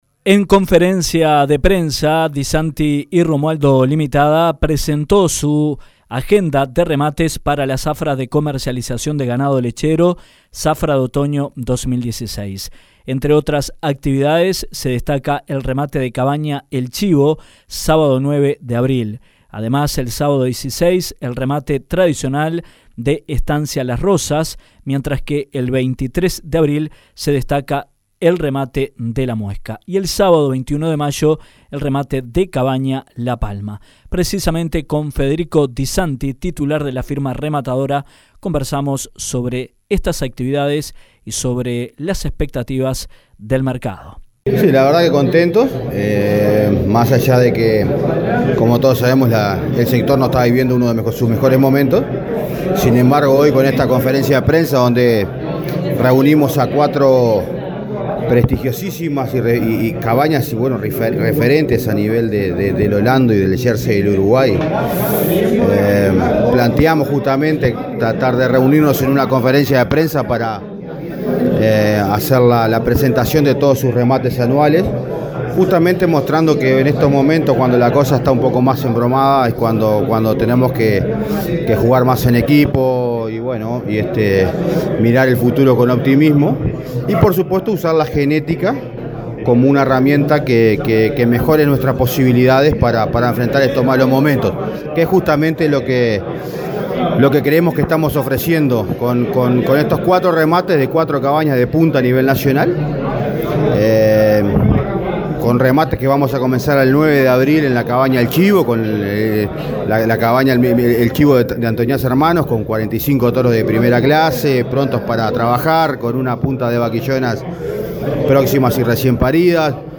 dijo ante los presentes en la conferencia de prensa realizada en la sede de la ANPL